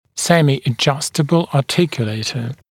[ˌsemɪə’ʤʌstəbl ɑːˈtɪkjuleɪtə][ˌсэмиэ’джастэбл а:ˈтикйулэйтэ]полурегулируемый артикулятор